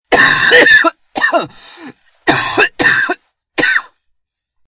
Download Coughing sound effect for free.
Coughing